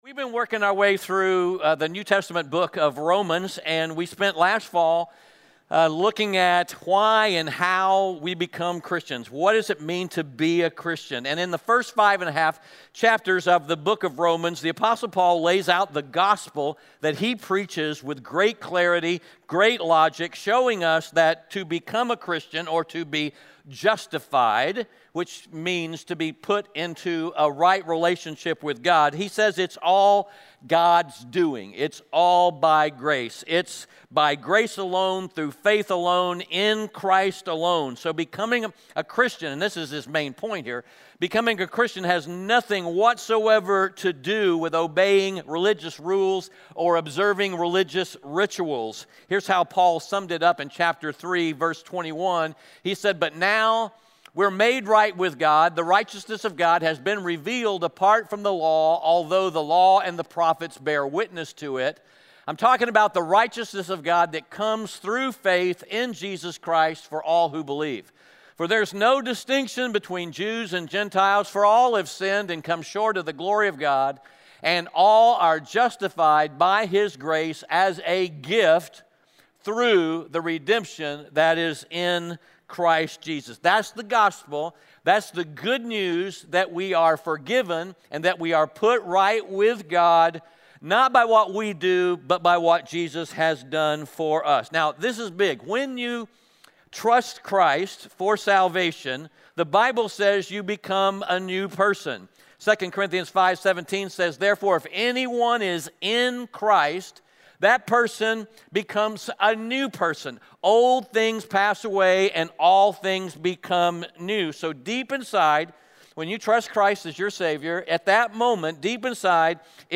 Romans 6:1-14 Audio Sermon Notes (PDF) Onscreen Notes Ask a Question *We are a church located in Greenville, South Carolina.